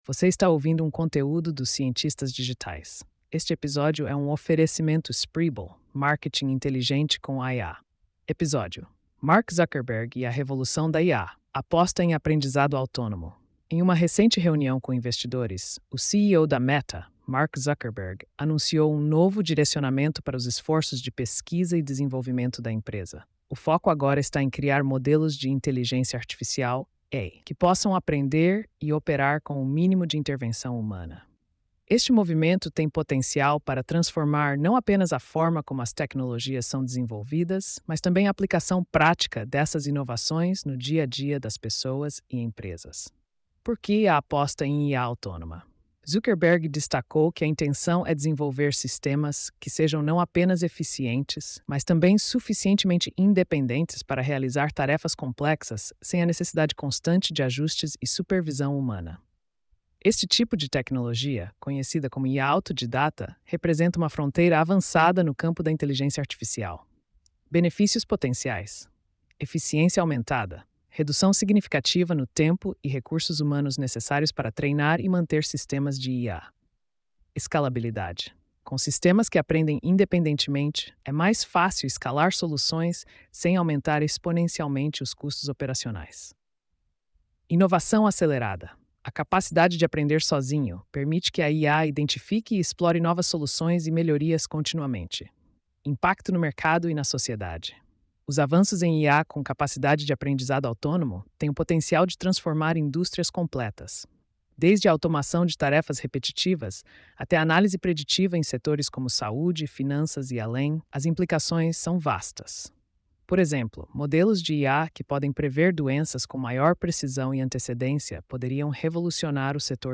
post-4113-tts.mp3